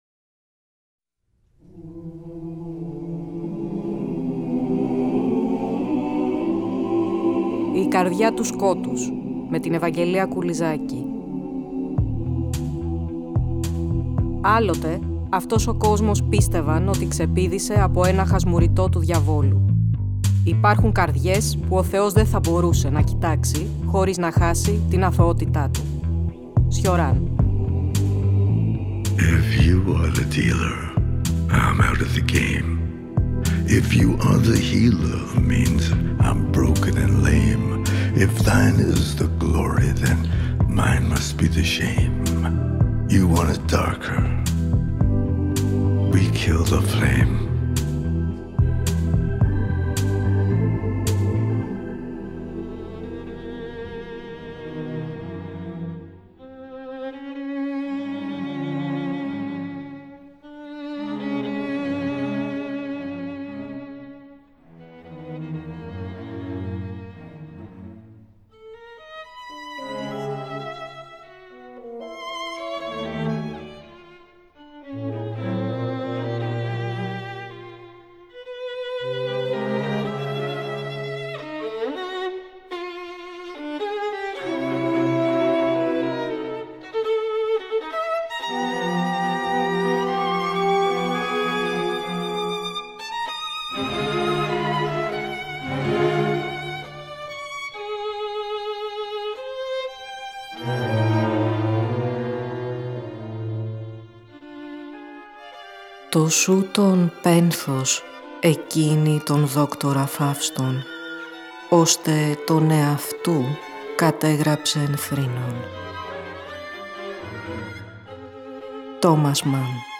Πλαισιώνουμε με Arnold Schoenberg , απ’ του οποίου τη θεωρία και τα έργα εμπνεύστηκε ο Τόμας Μαν για την περιγραφή των συνθέσεων του ήρωά του.